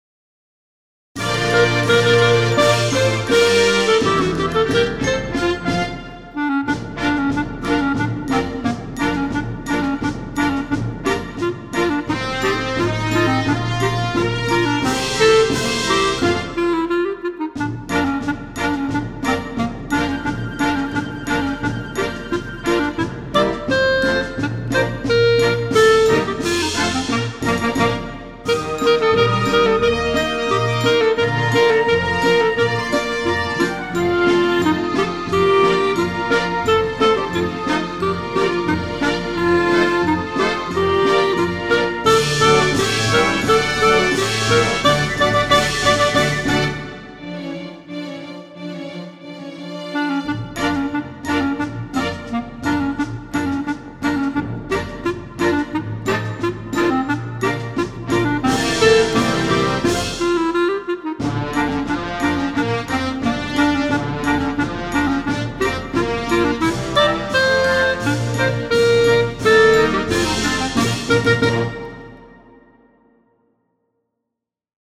full orchestral backing tracks
Level: Beginner